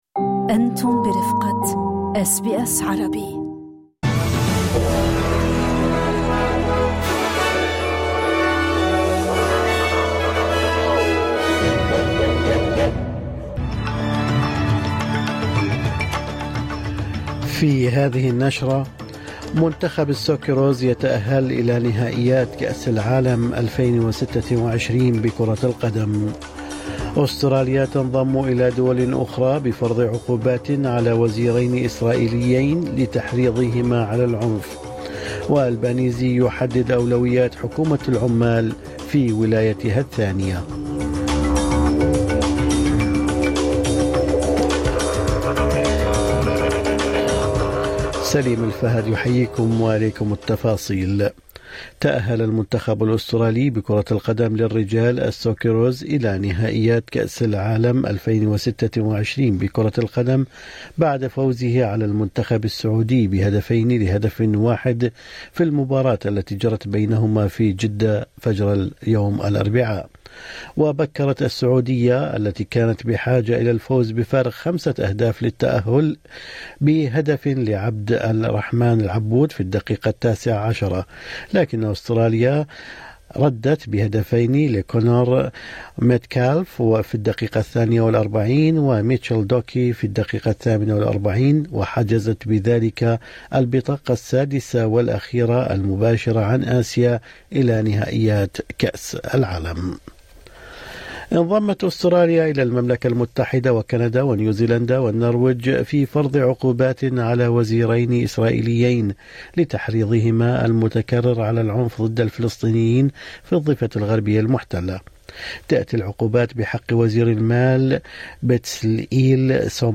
نشرة أخبار الصباح 11/6/2025